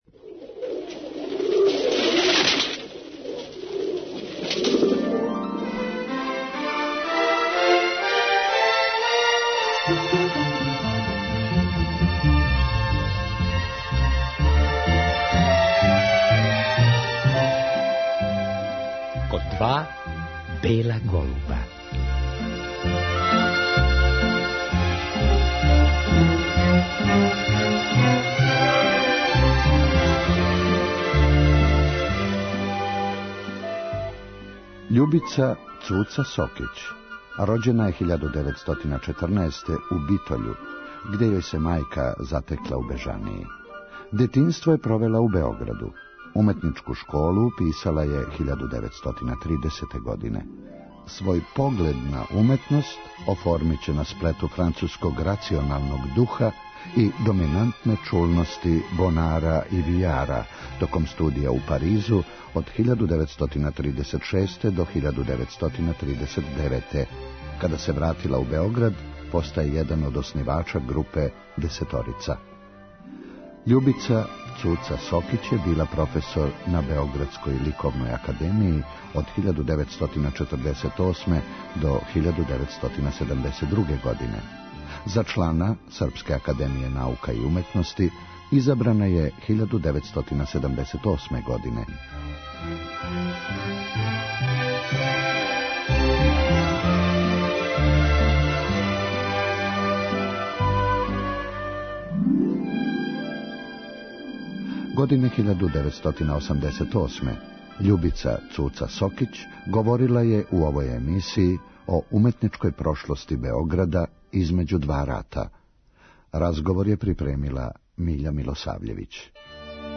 О свему томе сликарка је говорила у овој емисији 1987. године.